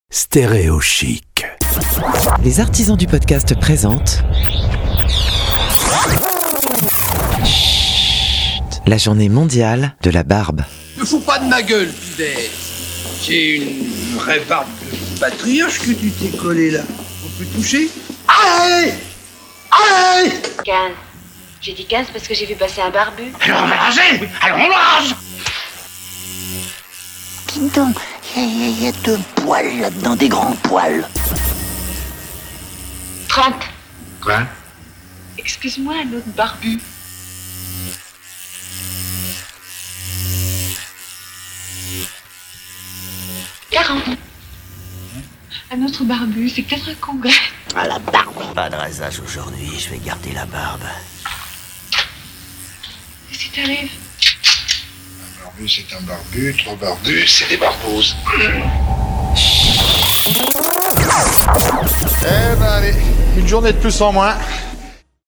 A l'occasion de la Journée Mondiale de la Barbe, le 5 septembre 2021, voici une immersion de 60 secondes avec Schhhhhht produit par les Artisans du Podcast.